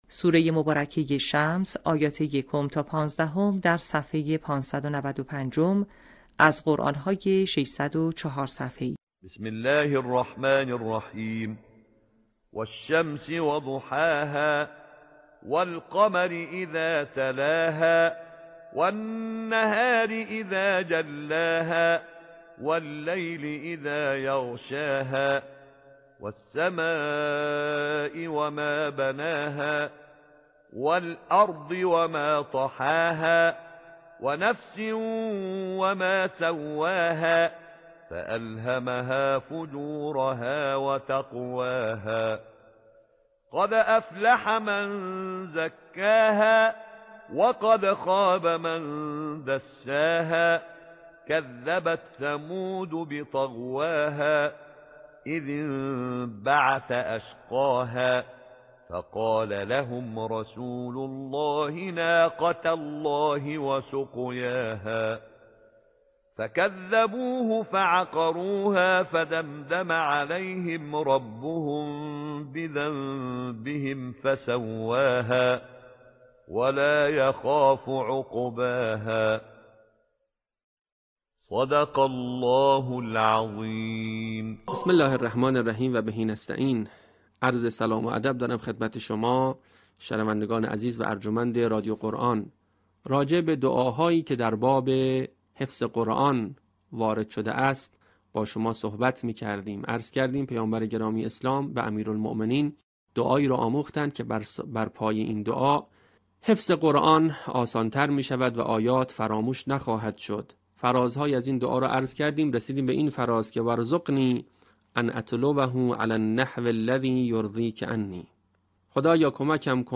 صوت | آموزش حفظ جزء ۳۰، آیات ۱ تا ۱۵ سوره شمس